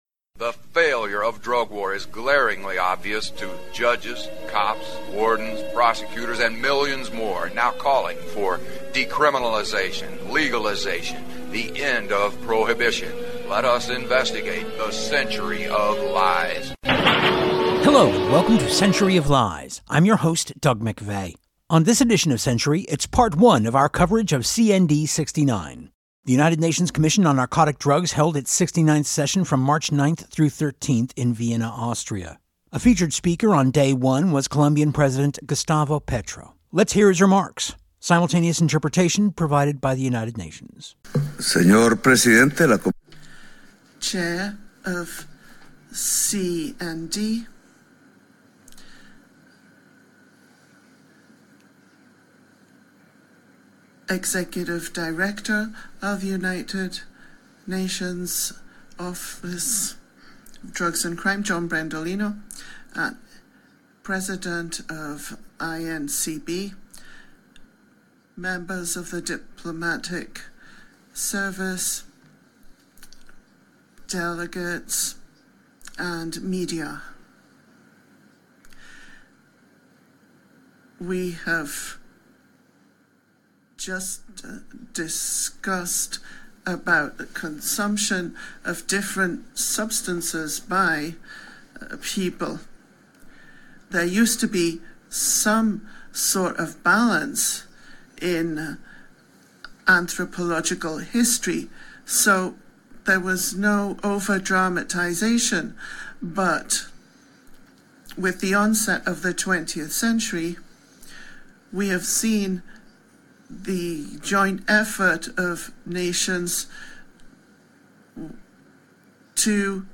The United Nations Commission on Narcotic Drugs held its 69th session this year from March 9-13 in Vienna, Austria. This week’s edition of Century is part one of our coverage, featuring addresses by Colombian President Gustavo Petro and by Ambassador Reza Najafi, Permanent Representative of the Islamic Republic of Iran to the UN in Vienna.